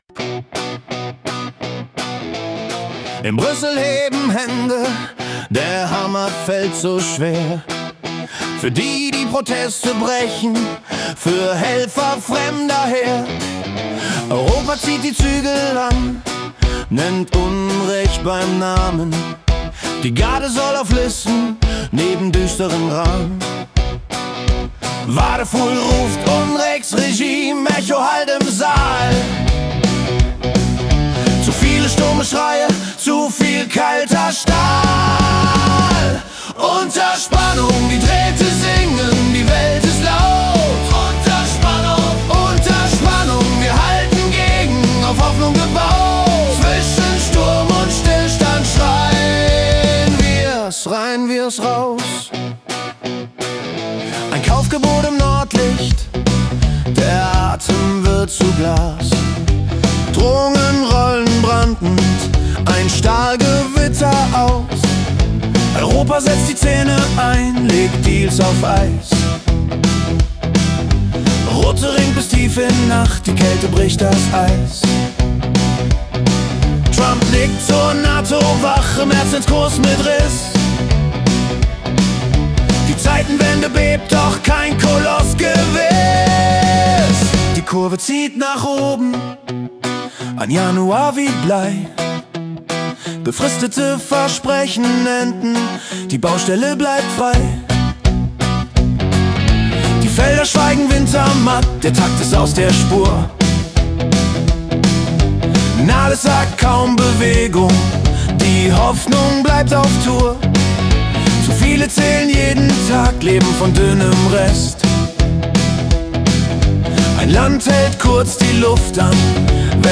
Januar 2026 als Rock-Song interpretiert.